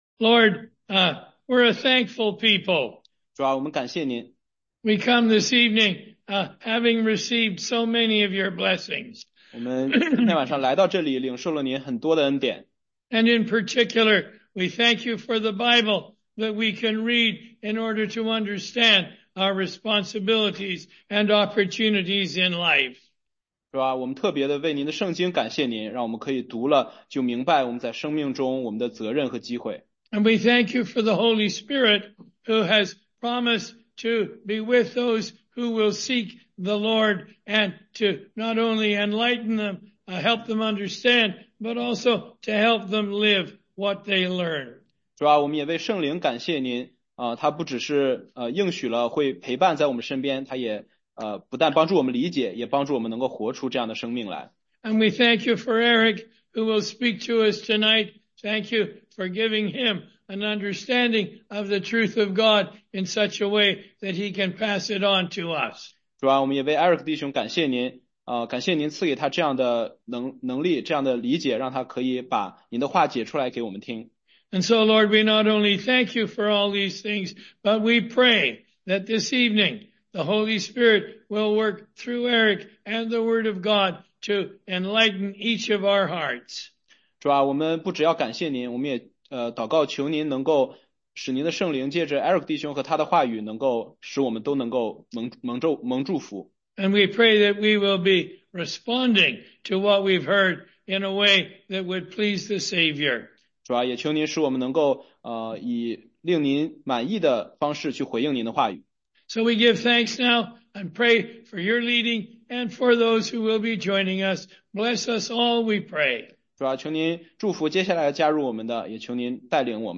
16街讲道录音 - 约翰福音解读—耶稣洁净圣殿（2章12-25节）
中英文查经